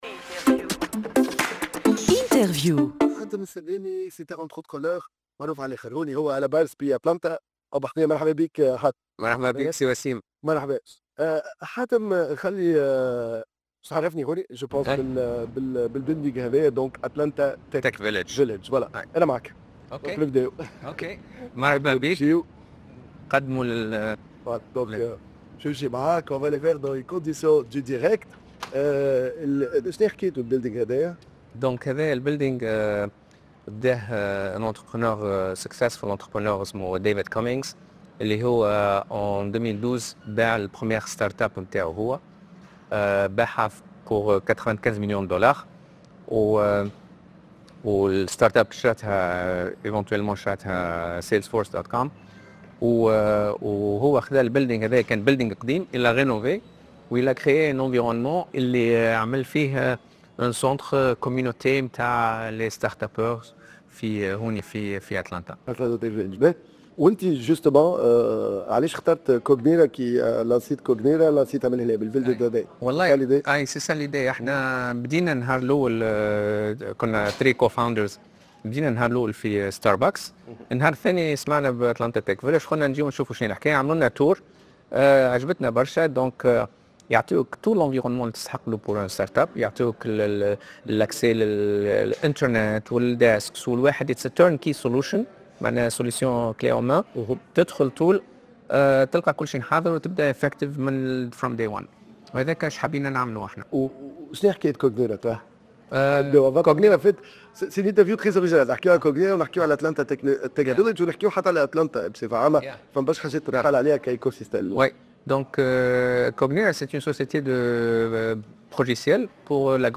Interview exclusive de ce serial entrepreneur tunisien en direct du Atlanta